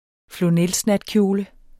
Udtale [ floˈnεls- ]